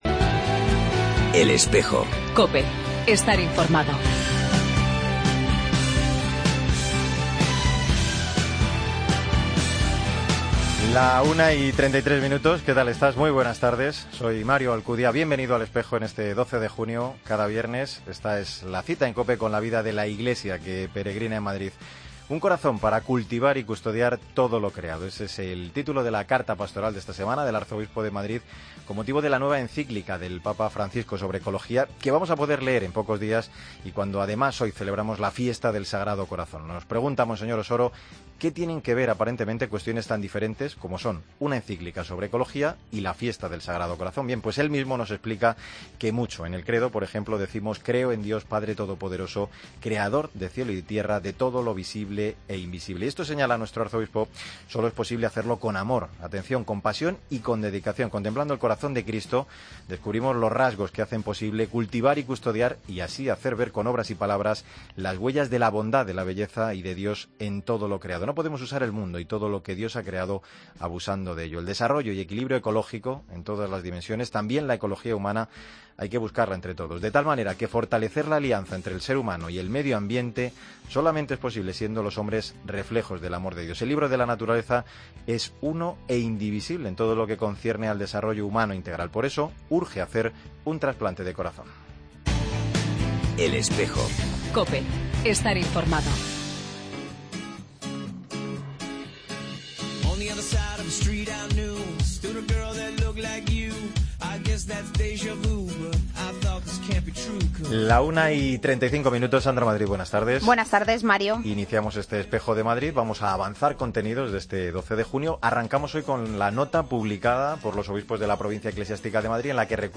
Nota de los obispos de la Provincia Eclesiástica de Madrid sobre la asignatura de Religión Católica. El comienzo de la FP en el Colegio diocesano San Ignacio de Loyola de Torrelodones. Entrevista